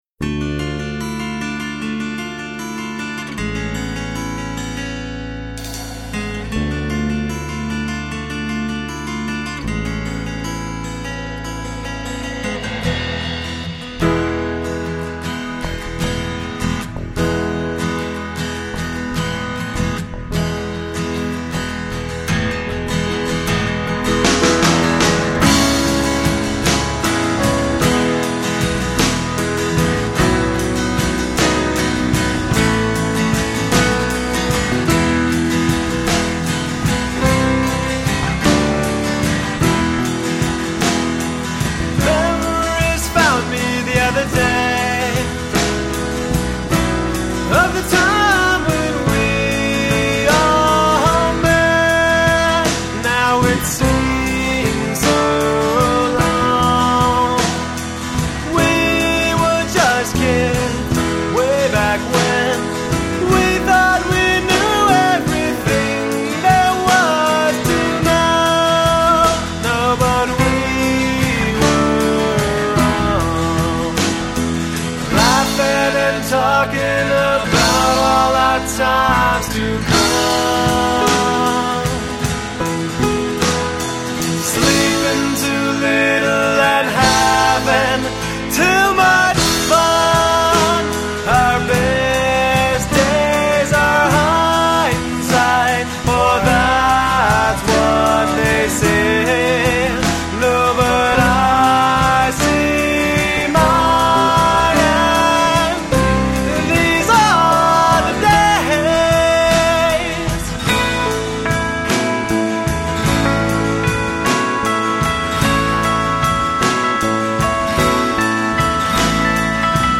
Virginia Arts Studios, Charlottesville, VA
Guitar, Vocals
Drums
Piano, Keyboards
Bass Guitar